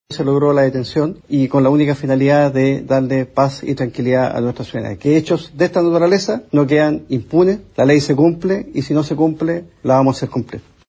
Así lo indicó el general director de Carabineros, Marcelo Araya, quien destacó que “hechos de esta naturaleza no quedan impunes. La ley se cumple, y si no se cumple, la vamos a hacer cumplir”.